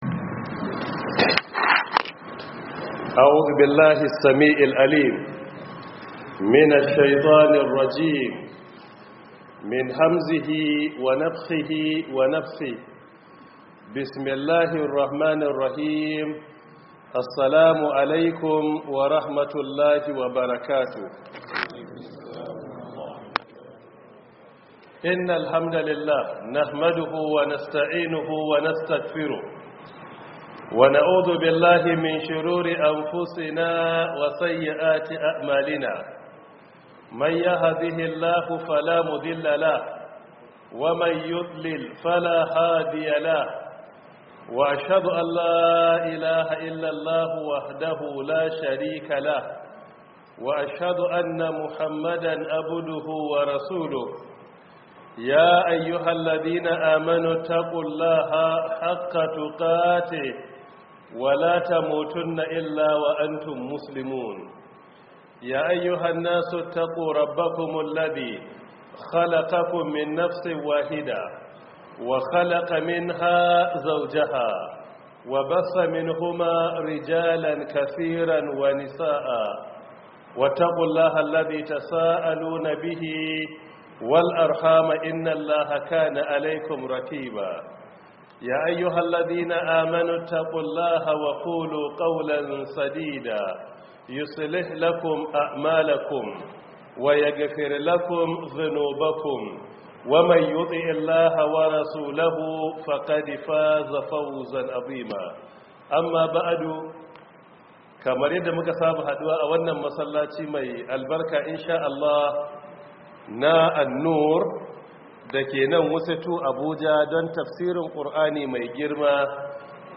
Download Audio From Book: 1447/2026 Ramadan Tafsir